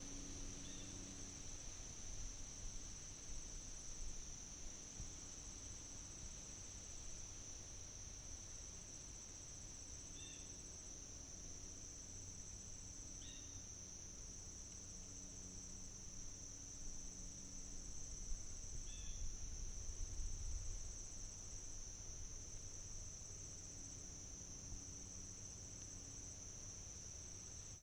Звуки поля
Здесь собраны аудиозаписи, которые передают бескрайние просторы, шелест колосьев, щебетание жаворонков и другие звуки сельской идиллии.
Звуки летнего поля: Шепот природы в теплые дни